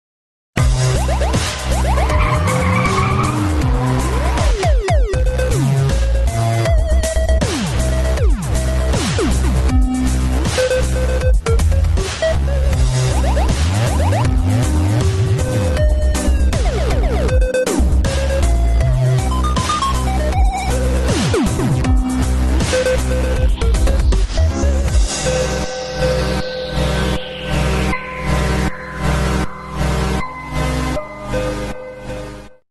Nice boss music.